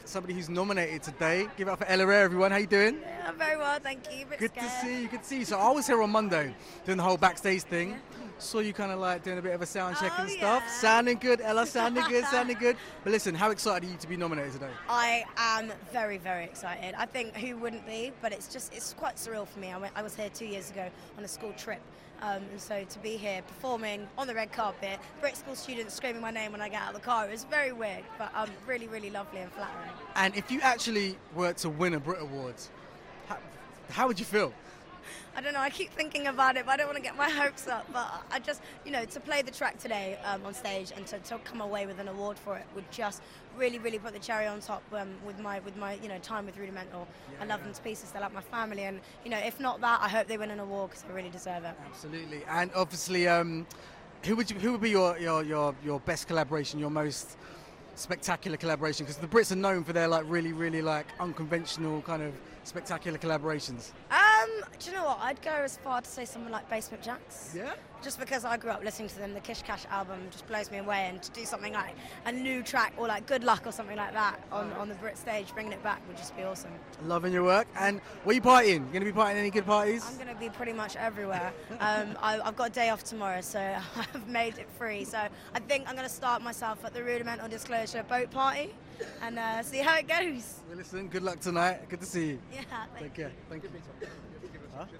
Ella Eyre on the red carpet at the Brits!